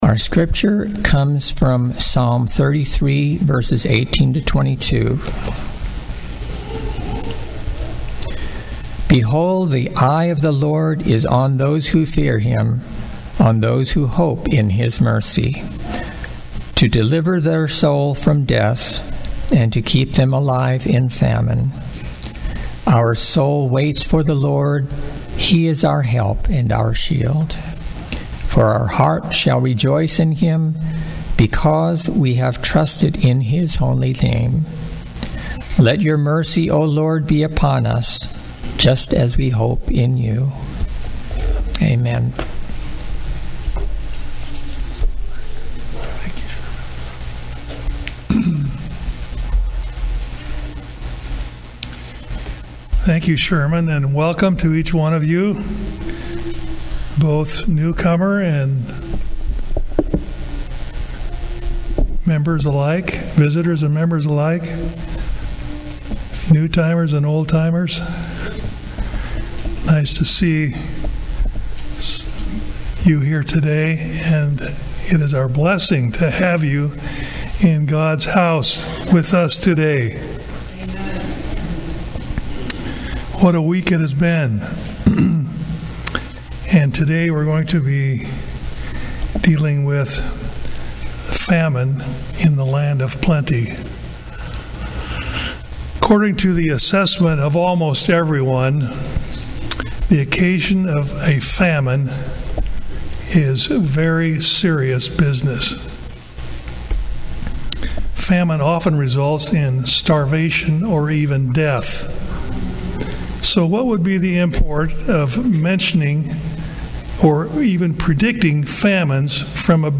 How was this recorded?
Seventh-day Adventist Church, Sutherlin Oregon